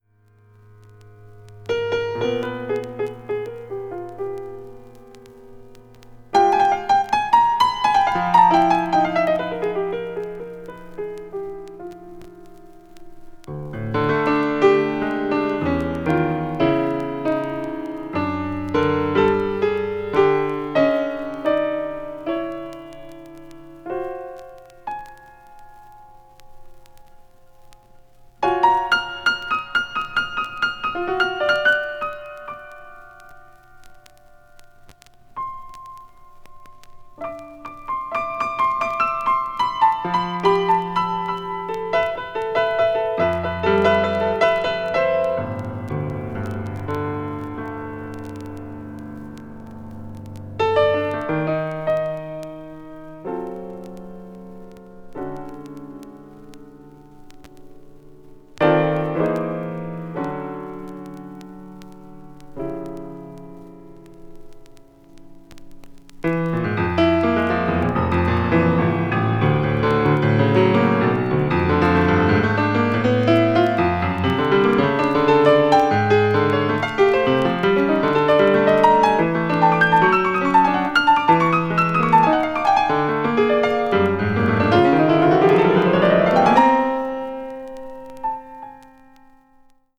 Solo Piano
薄く細かいスリキズによる軽いチリノイズが入る箇所あり